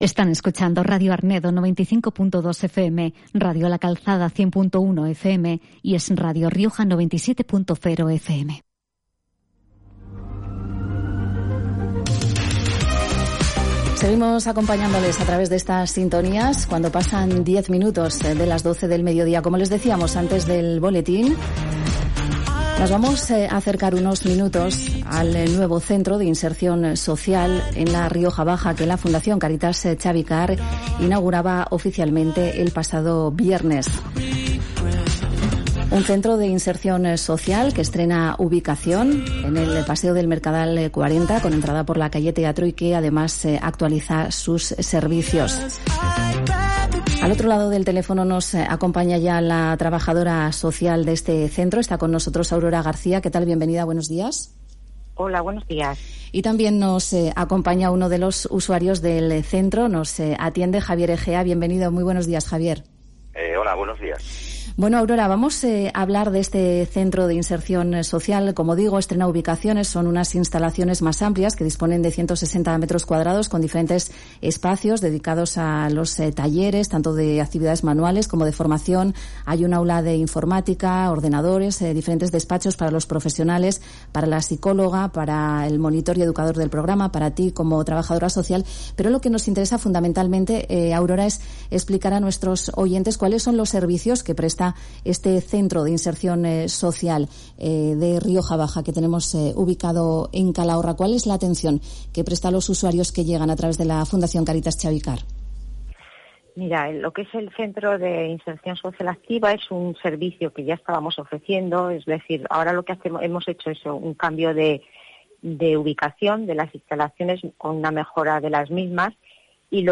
Entrevista en Radio Arnedo
Escuchar el testimonio de uno de los usuarios del Centro de Inserción Social de Rioja Baja y las explicaciones de una de las profesionales son la mejor manera de conocer este recurso.